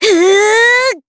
Cecilia-Vox_Casting2_jp.wav